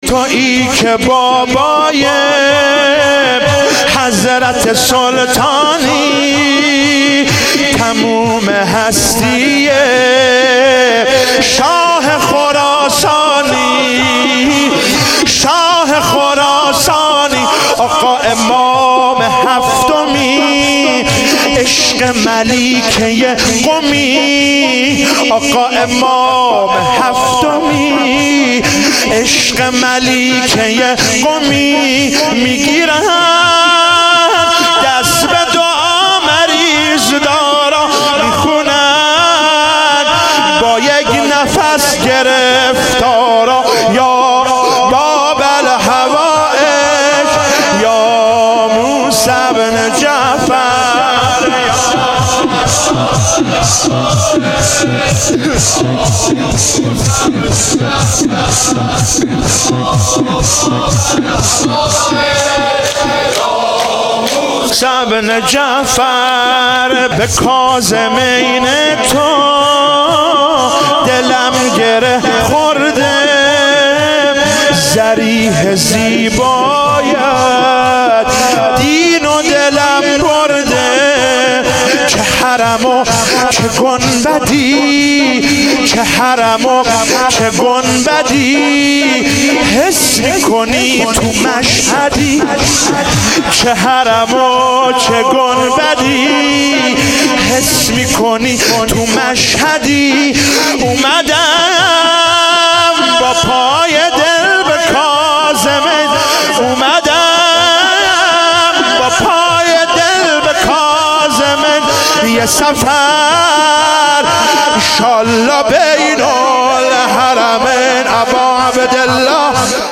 شور - تویی که بابای حضرت سلطانی